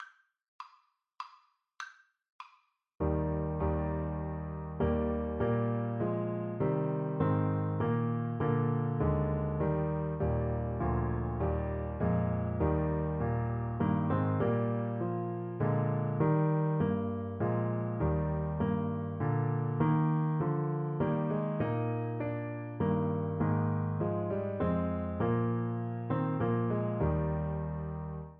Christian
Alto Saxophone
3/4 (View more 3/4 Music)
Classical (View more Classical Saxophone Music)